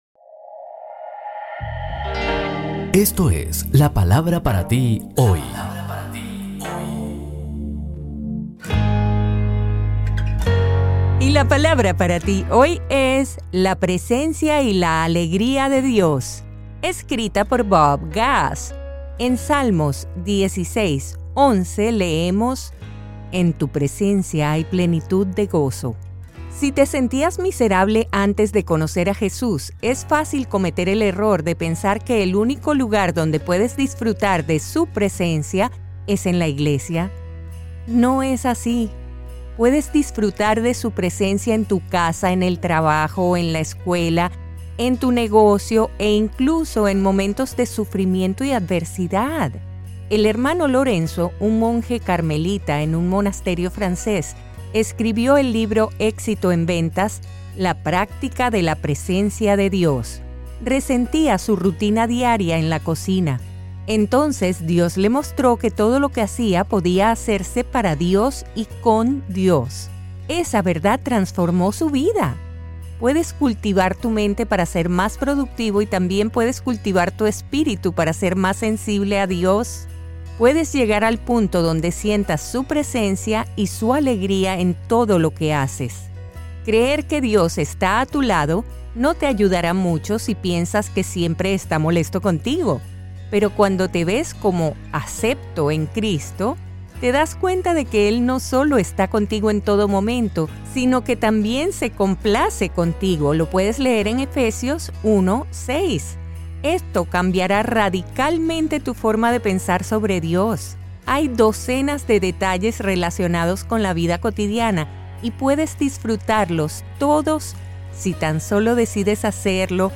Esperamos que estés disfrutando verdaderamente de estos magníficos devocionales escritos por Bob Gass y narrados por la querida Elluz Peraza. El de hoy es para que disfrutes de la presencia de Dios y te alegres como Él nos permite.